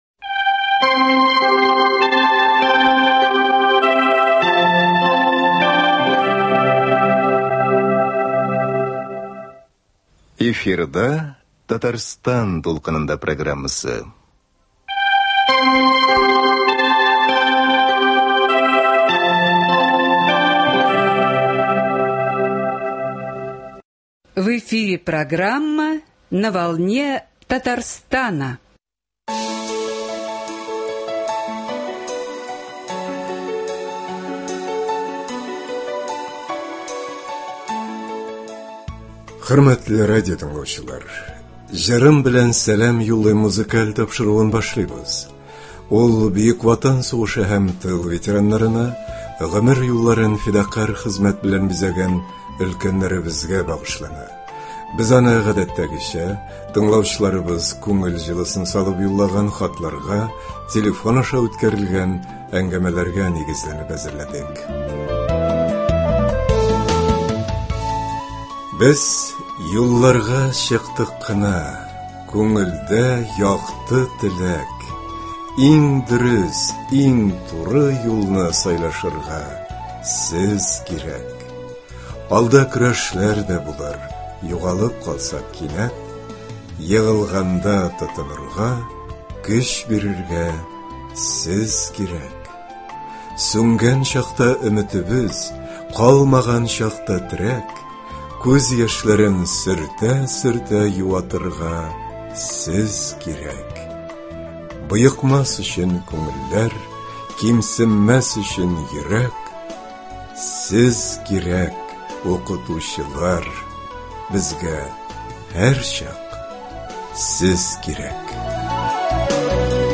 Укытучылар көненә багышланган музыкаль программа.